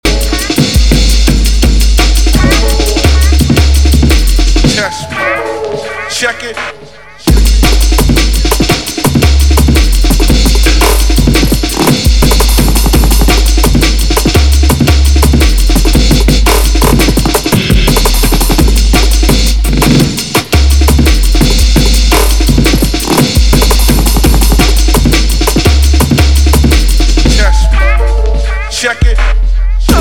TOP >Vinyl >Drum & Bass / Jungle
TOP > Jungle